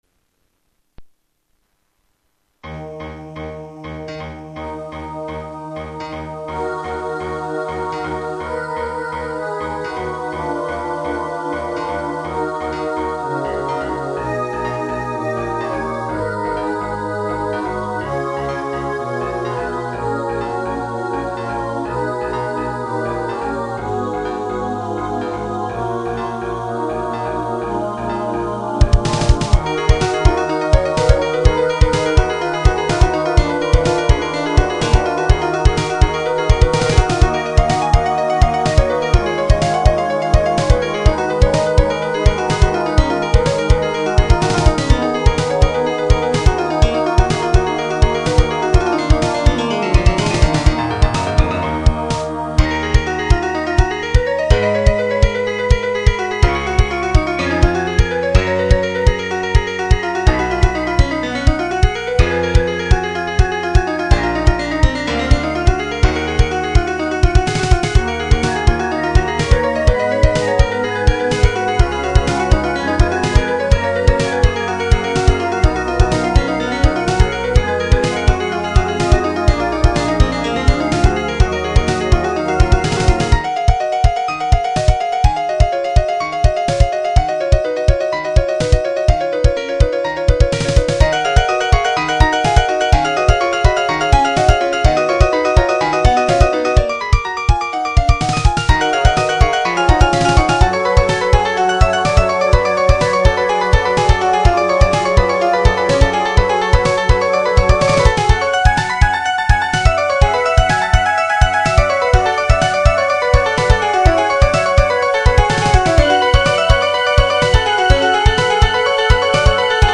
Powstał pod wpływem szczytnej idei połączenia muzyki poważnej z popularnym Beatem.